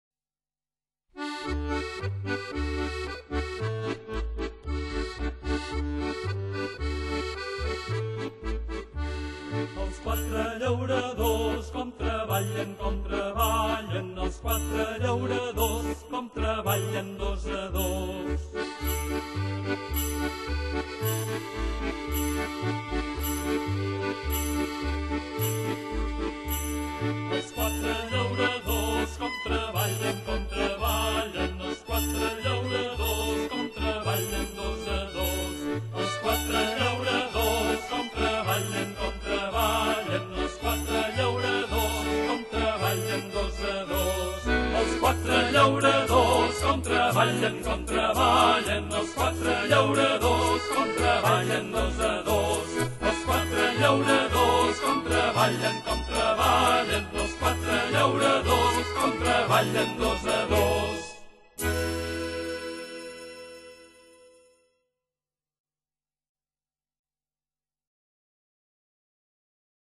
Tots Sants - Danses
Tradicional catalana